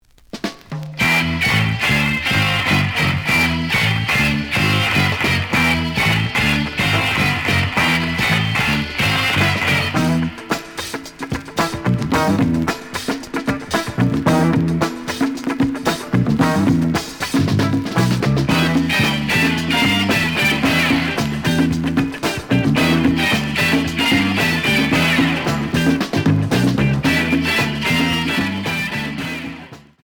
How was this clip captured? The audio sample is recorded from the actual item. Slight noise on both sides.